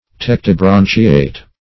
Search Result for " tectibranchiate" : The Collaborative International Dictionary of English v.0.48: Tectibranchiate \Tec`ti*bran"chi*ate\, a. [L. tectus (p. p. of tegere to cover) + E. branchiate.]